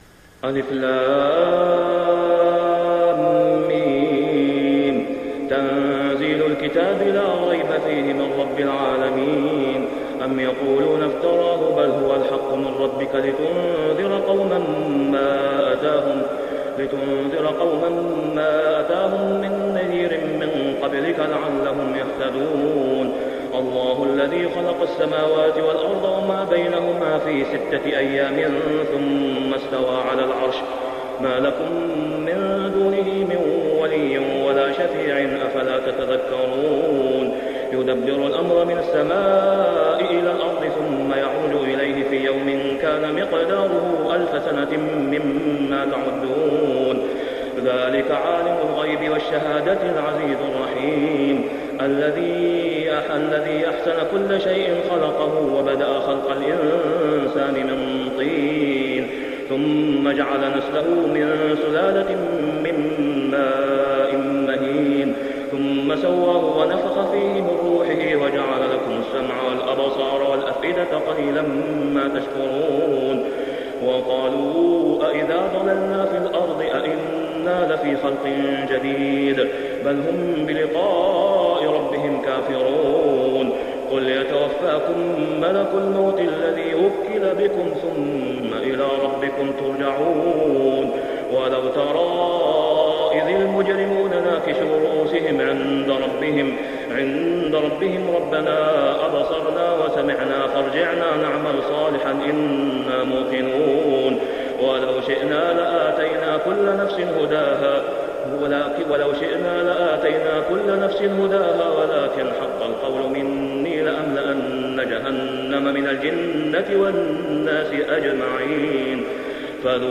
سورة السجدة > السور المكتملة للشيخ أسامة خياط من الحرم المكي 🕋 > السور المكتملة 🕋 > المزيد - تلاوات الحرمين